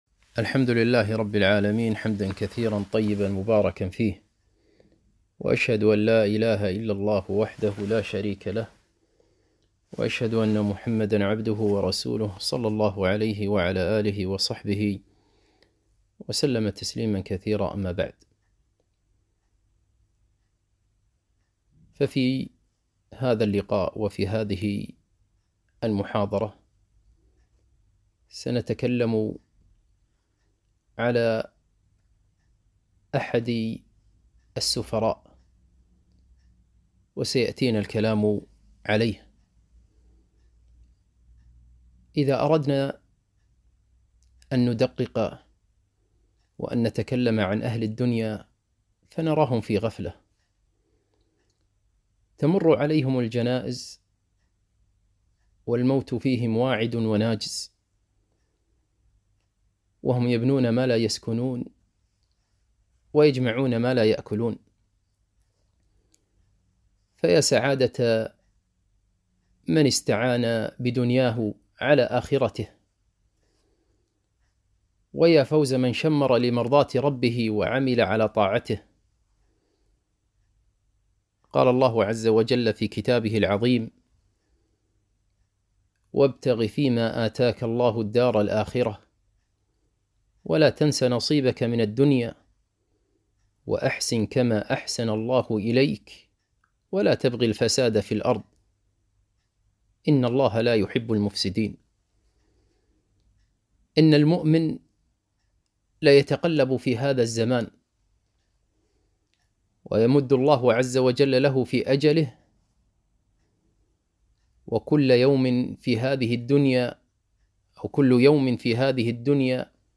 محاضرة - شهر يغفل الناس عنه - دروس الكويت